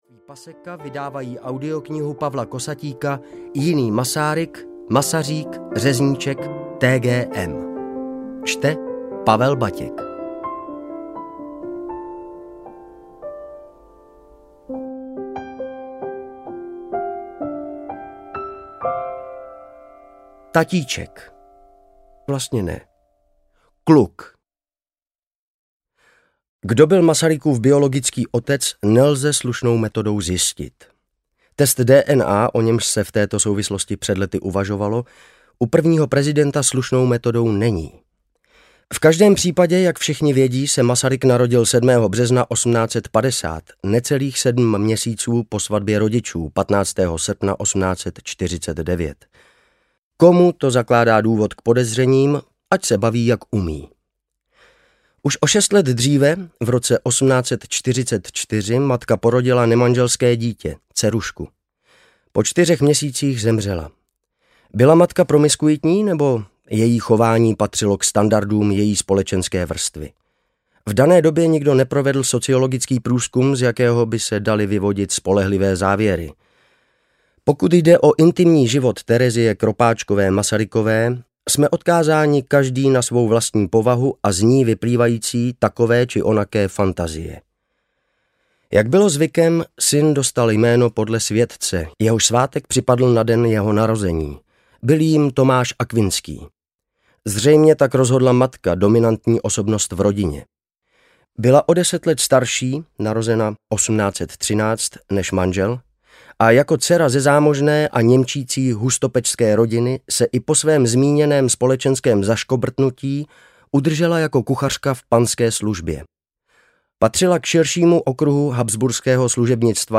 Audio knihaJiný T.G.M.
Ukázka z knihy